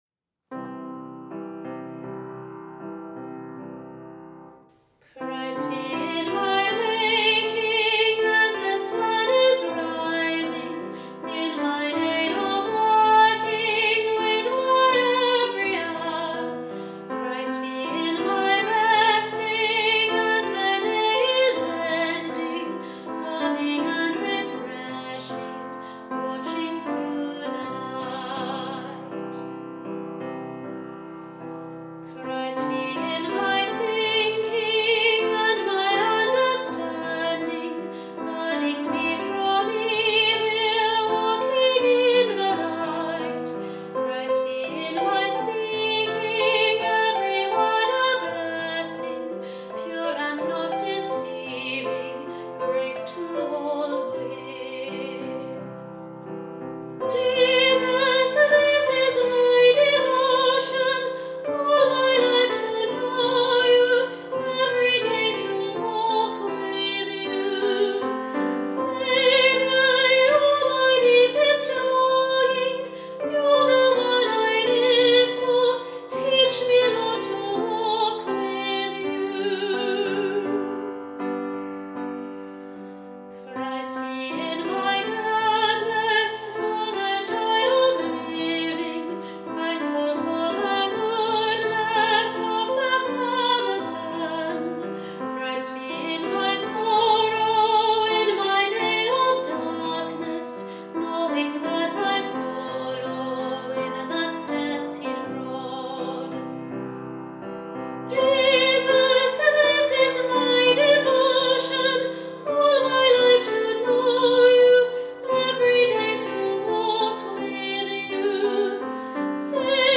Final Hymn Christ be in my waking